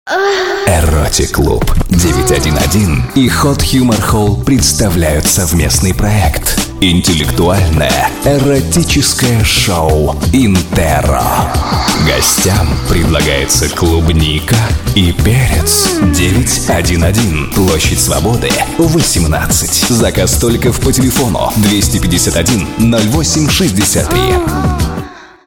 Professioneller russischer Sprecher für TV/Rundfunk/Industrie.
russischer Sprecher
Sprechprobe: Industrie (Muttersprache):
russian voice over talent